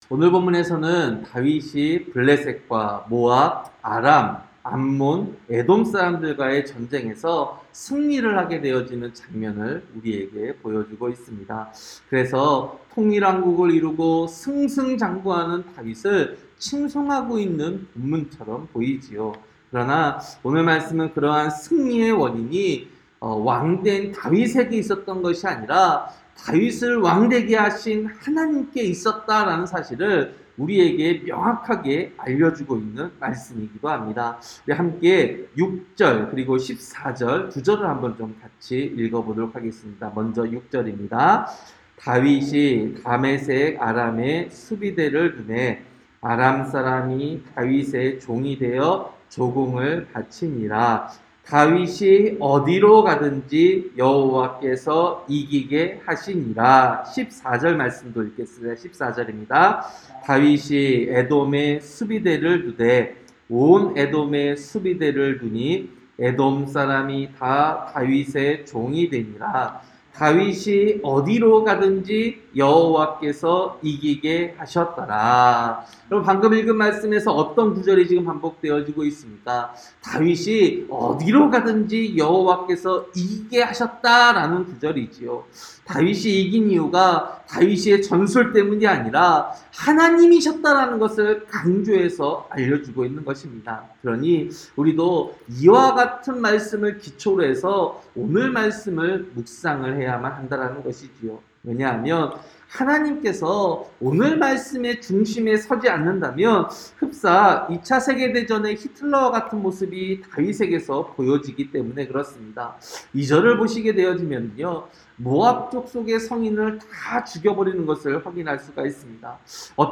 새벽설교-사무엘하 8장